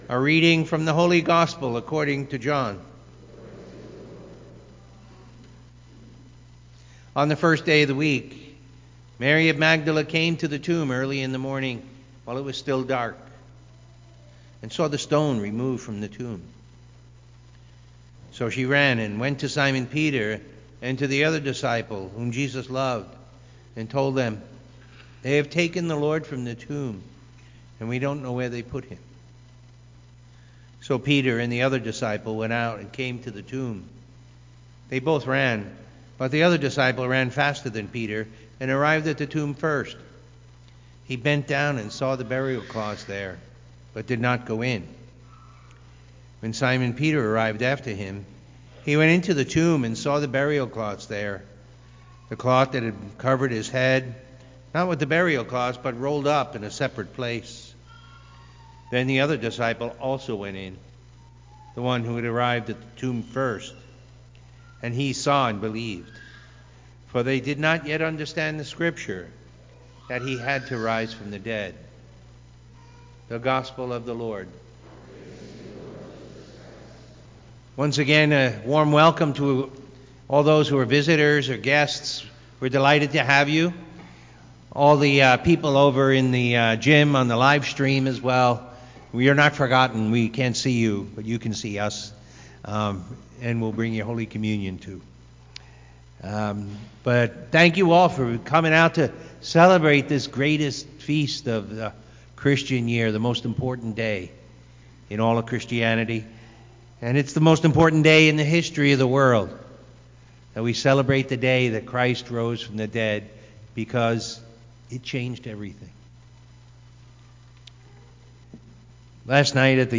Listen to the homily from the Sunday Mass and meditate on the Word of God.